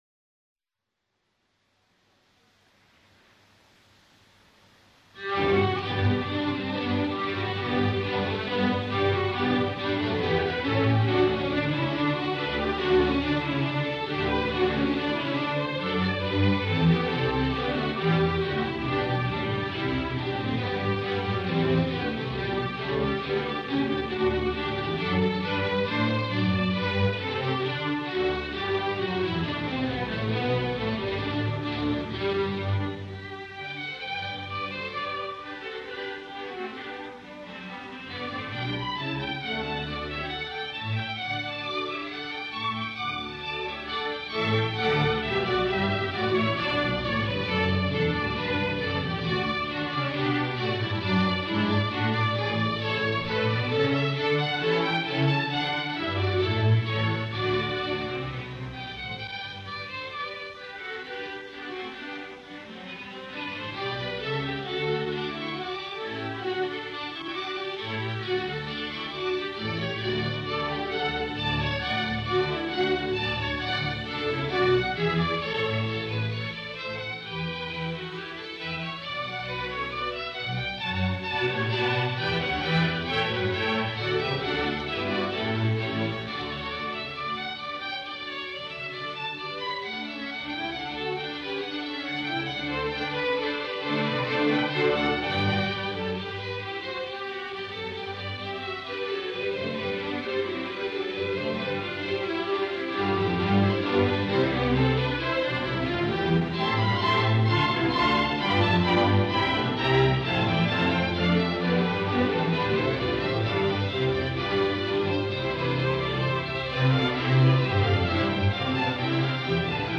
in G major